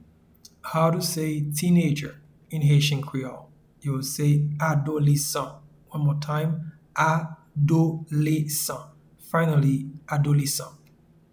Pronunciation and Transcript:
Teenager-in-Haitian-Creole-Adolesan.mp3